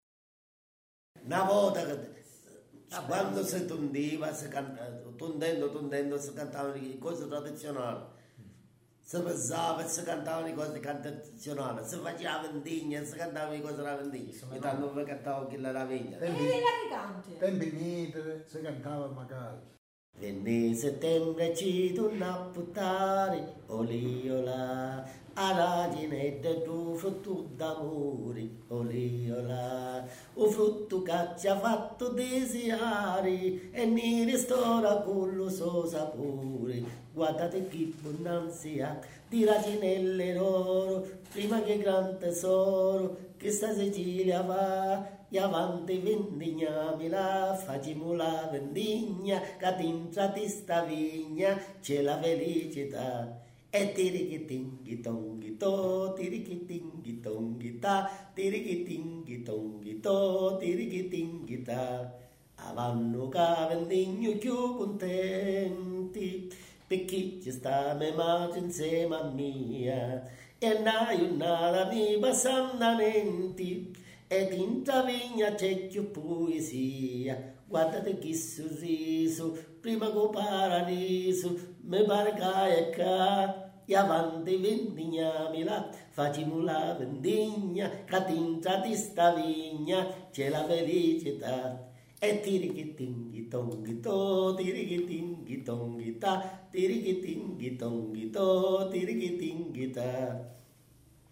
SERENATE E FILASTROCCHE